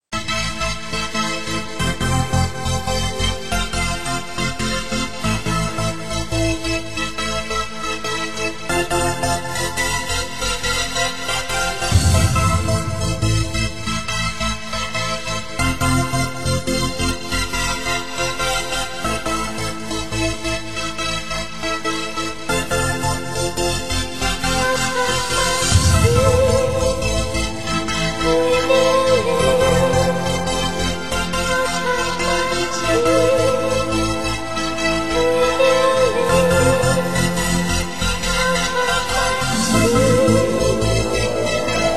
Genre: Funky House